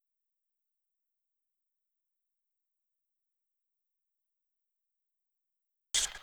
4 Harsh Realm Vox Clicks Long.wav